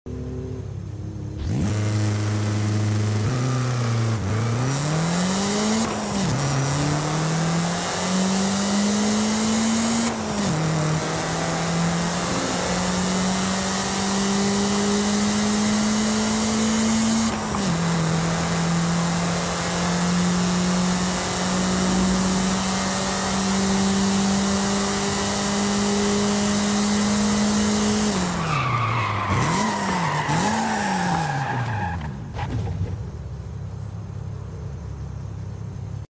1994 Toyota Celica GT Four ST205 sound effects free download
1994 Toyota Celica GT-Four ST205 Launch Control & Sound - Forza Horizon 5